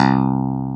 CLV_ClavDAC_3 2a.wav